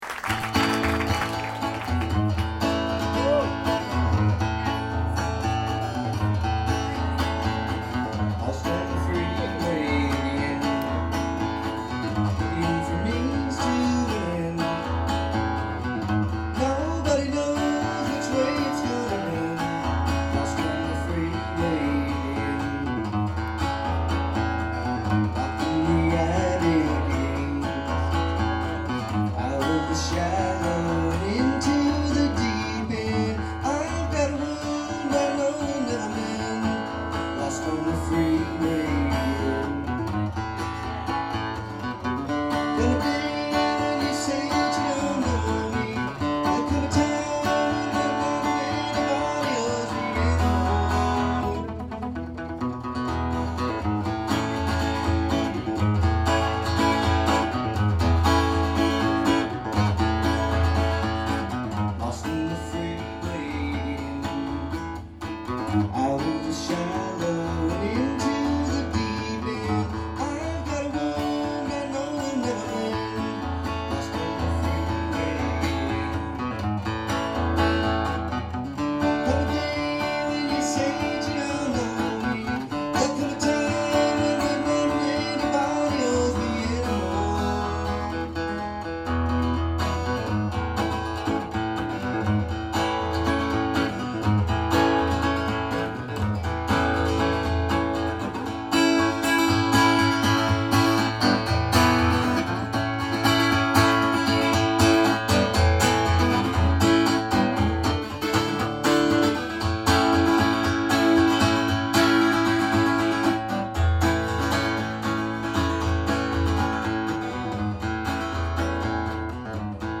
The quality is outstanding.